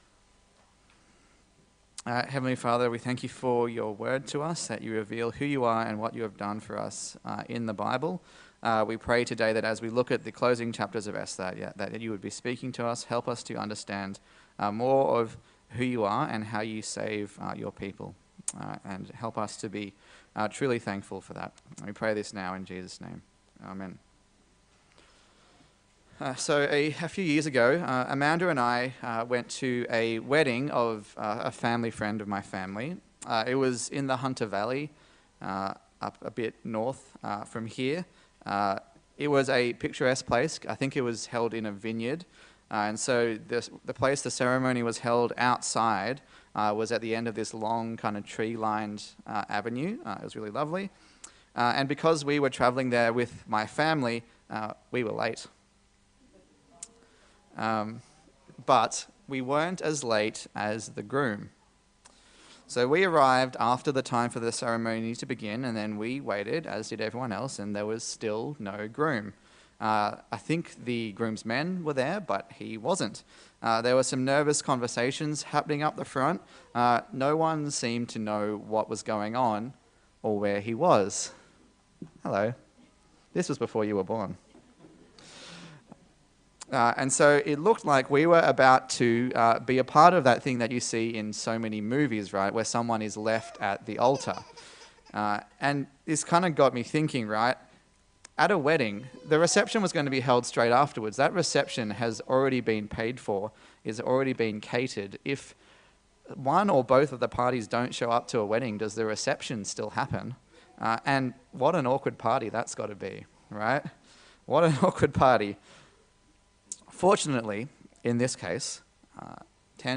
Service Type: Sunday Service A sermon in the series on the book of Esther